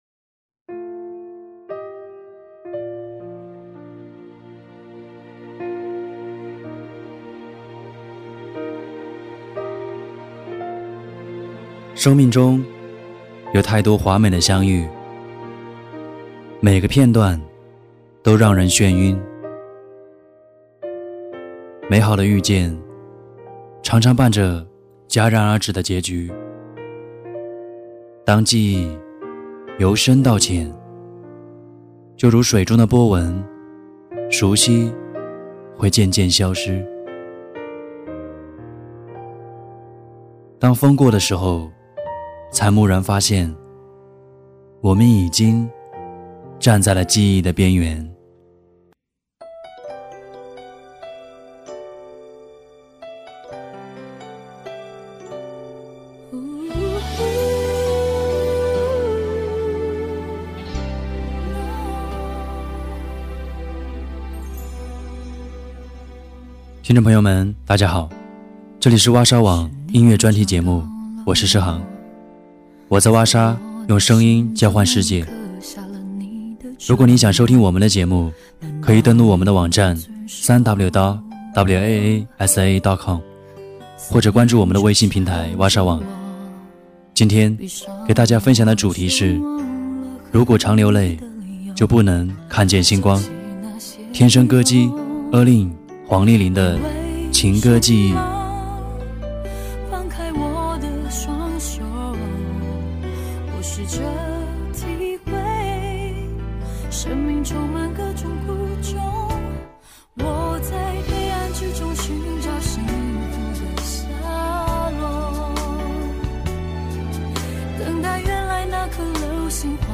歌曲【今晚你想念的人是不是我】 你其实不喜欢我跟别人一起去玩，心情不好却自己一个人流走游荡。
歌曲【不是不满足】 不是不满足，只是会想假如，有过疯狂和任性糊涂。